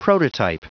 Prononciation du mot prototype en anglais (fichier audio)
Prononciation du mot : prototype